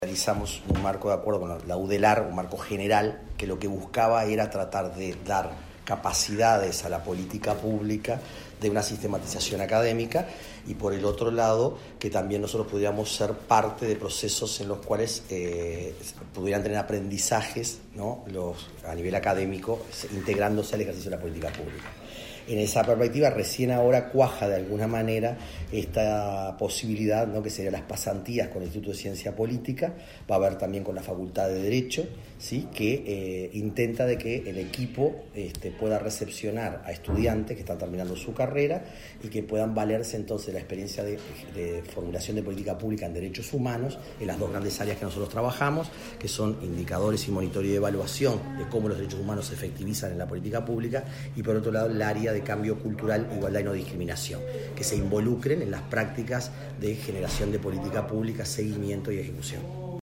La Secretaría de Derechos Humanos, de la Presidencia, acordó con la Facultad de Ciencias Sociales de la Universidad de la República recibir en pasantías a estudiantes de la Licenciatura en Ciencia Política. El objetivo es brindarles herramientas en la formulación de políticas públicas sobre derechos humanos, expresó el titular de la secretaría, Nelson Villarreal.